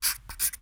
Sonidos: Acciones humanas
Sonidos: Oficina